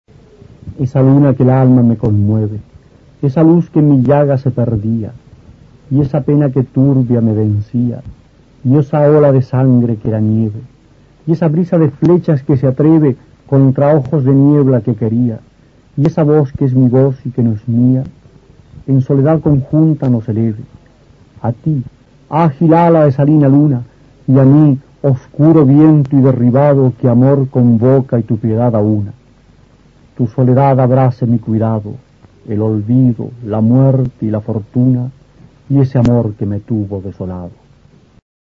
Aquí se puede escuchar al poeta chileno Roque Esteban Scarpa (1914-1995) recitando uno de sus sonetos.
Soneto